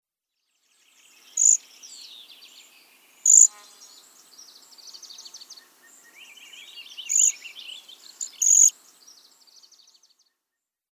На этой странице собраны звуки свиристели – красивые и звонкие трели этой яркой птицы.
Звуки пения свиристеля Американский соловей